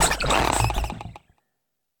Cri de Délestin dans Pokémon Écarlate et Violet.